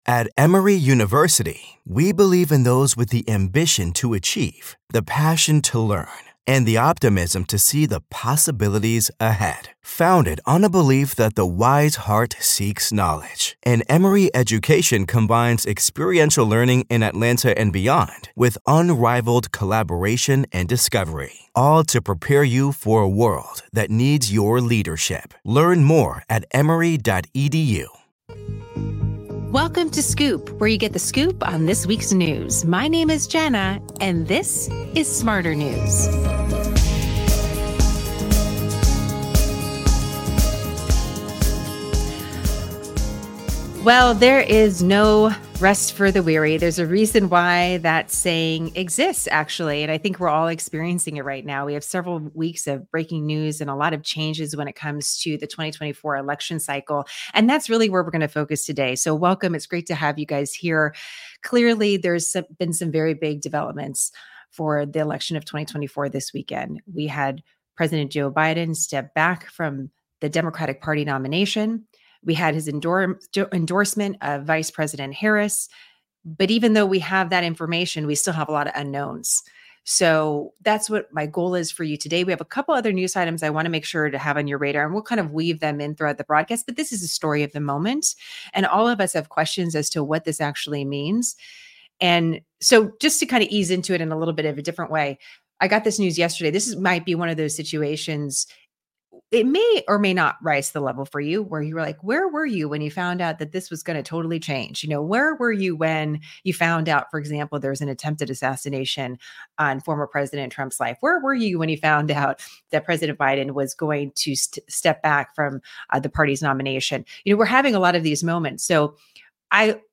RSS 🧾 Download transcript Summary Breaking news shared during our broadcast including new remarks from Vice President Harris, a new endorsement from fmr. House Speaker Pelosi (D-CA) and the Secret Service Director takes questions from Congress on the attempted assassination of fmr. Pres. Trump.